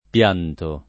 pianto [ p L# nto ]